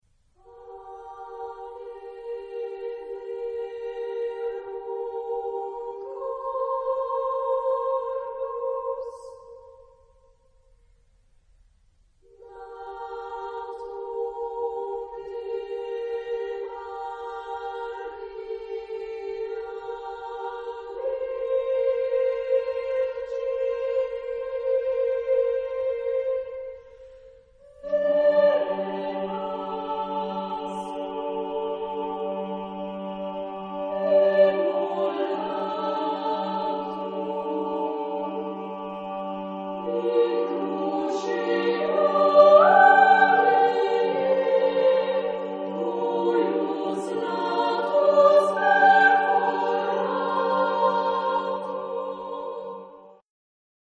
Genre-Style-Form: Sacred ; Prayer ; Hymn (sacred)
Type of Choir: SATB  (4 mixed voices )
Tonality: G aeolian ; modal